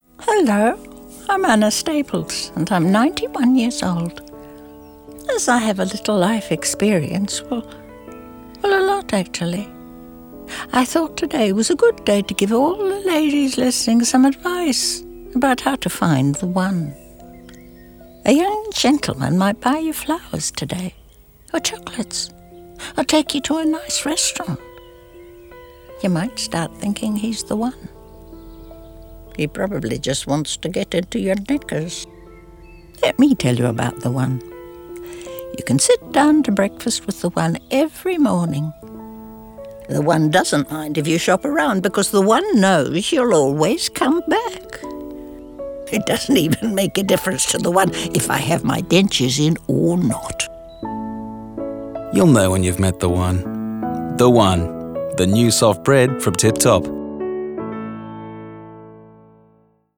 In the spot, a 91-year-old woman draws on her life experience to offer love advice to lady listeners.